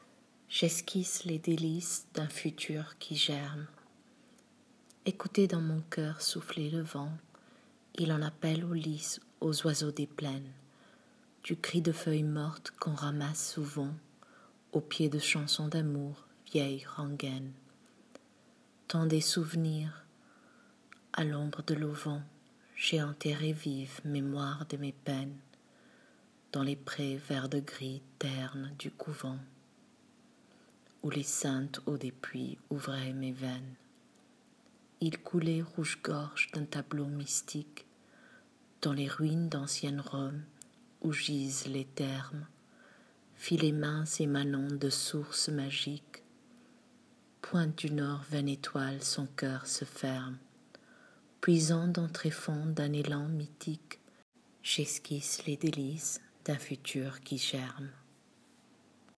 Lecture du poème: